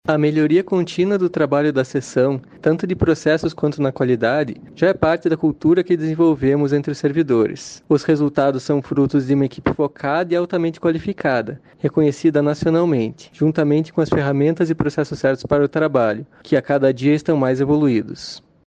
Sonora do chefe da Seção de Computação Forense